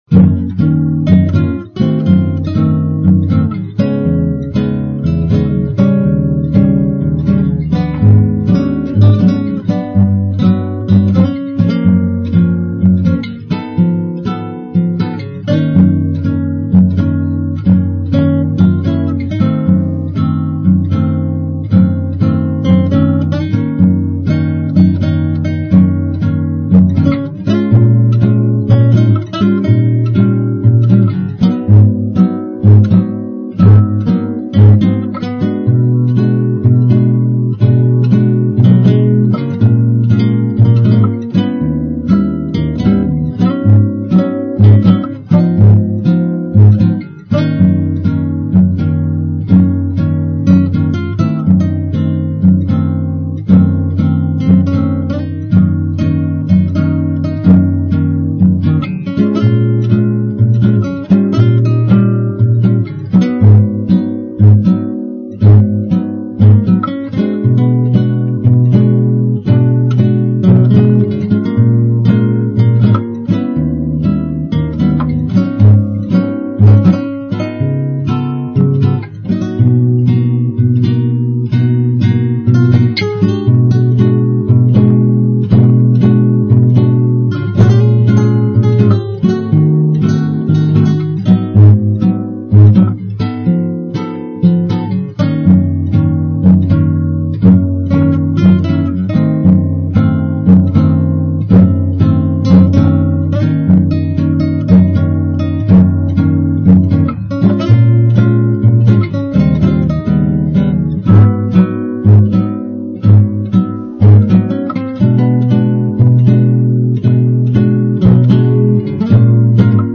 SPAZIO BOSSANOVA
Possiamo anche eseguire, in luogo dell'accordo E°, un G°.
Abbiamo introdotto, alla quarta battuta, una variante costituita dagli accordi E13 e E7/5+.